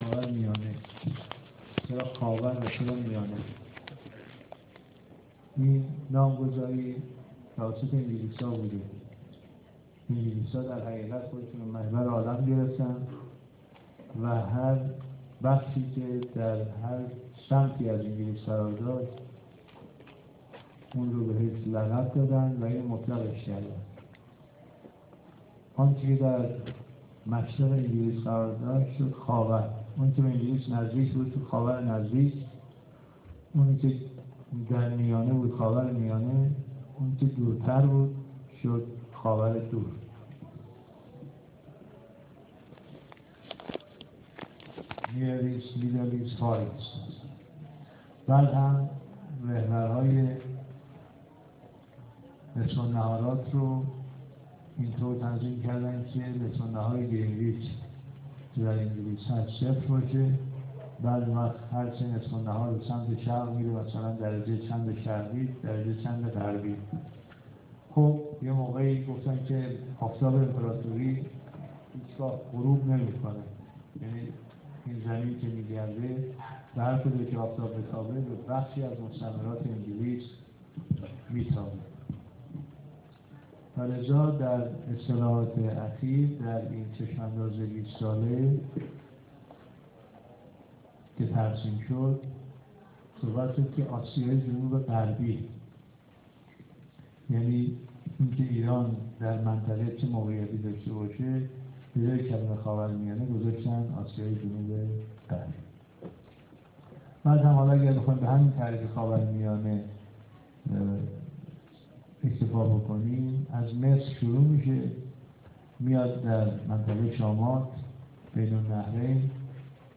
سخنرانی دکتر ولایتی در دانشکده حقوق دانشگاه تهران - 8 اردیبهشت 1392